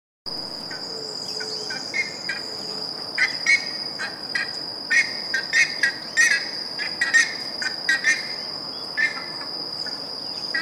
Bandurria Boreal (Theristicus caudatus)
Nombre en inglés: Buff-necked Ibis
Fase de la vida: Adulto
Localidad o área protegida: Parque Nacional Calilegua
Condición: Silvestre
Certeza: Observada, Vocalización Grabada